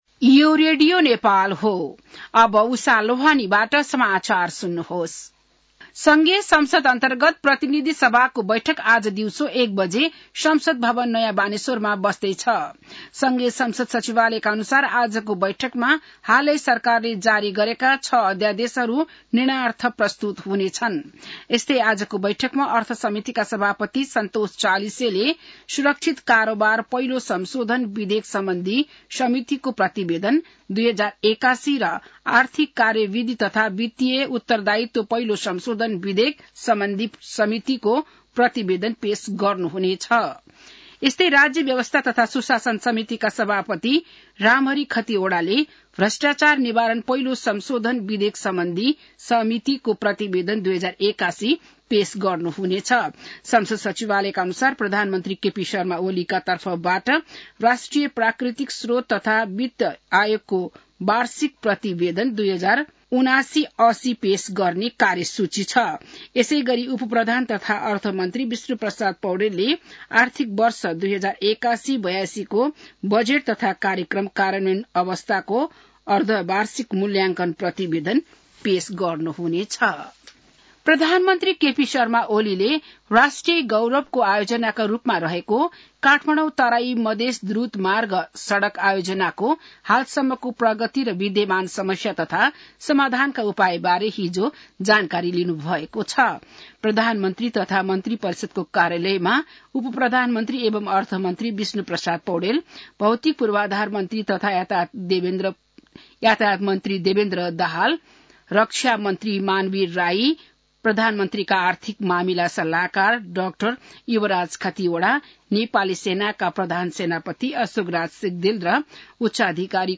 बिहान १० बजेको नेपाली समाचार : २५ माघ , २०८१